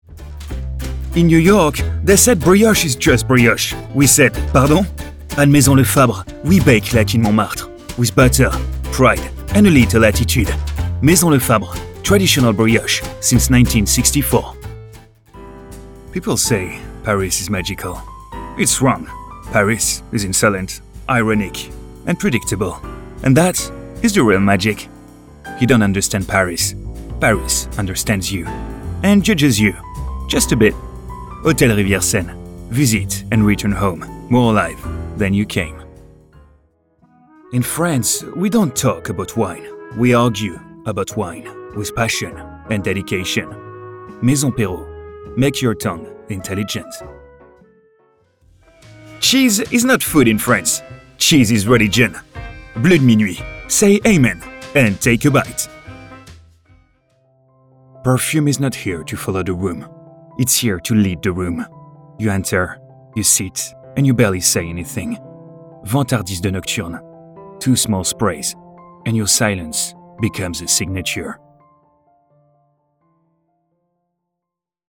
Male
Confident, Engaging, Friendly, Versatile
I got my own professional home studio with a high quality recording set up. About my voice: I got a friendly, smooth and warm tone .
English French accent Voice Reel Demo 20....mp3
Microphone: TLM 102
Audio equipment: Universal Audio Apollo Twin MKII, Waves plug ins, Fully soundproof room